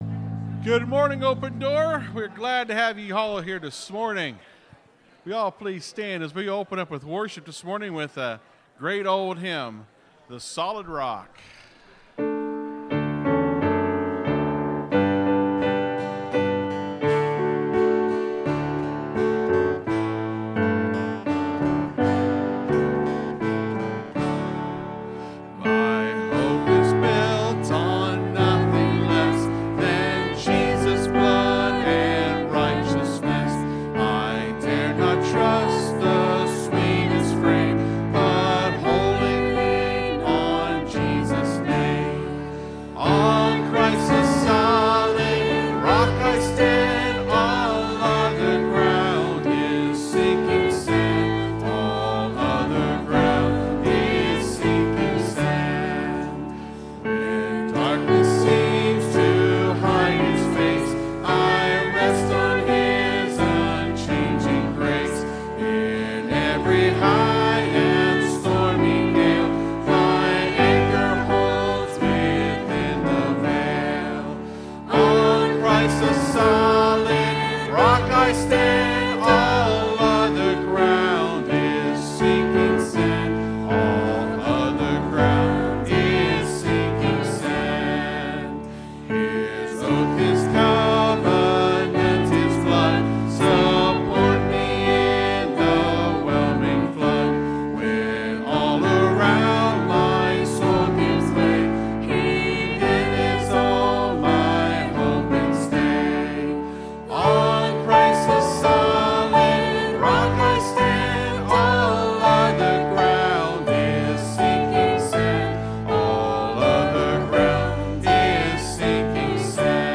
(Sermon starts at 24:15 in the recording).